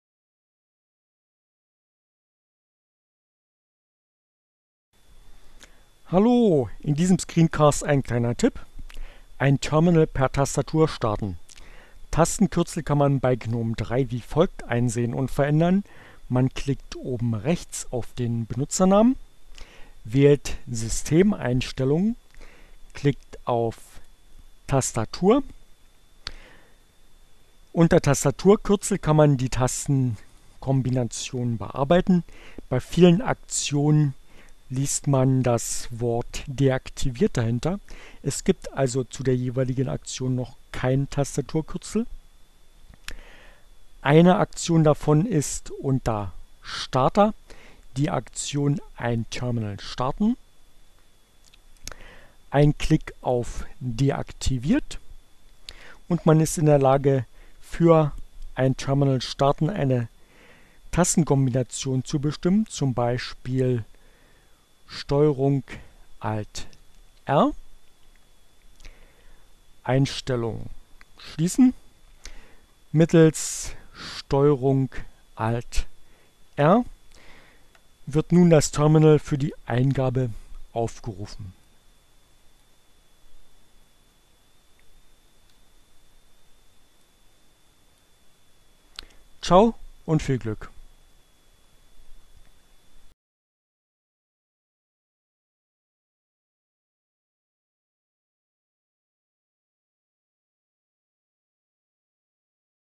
Tags: CC by-sa, Fedora, Gnome, Linux, Neueinsteiger, Ogg Theora, ohne Musik, screencast, gnome3, Terminal, Tastenkombinationen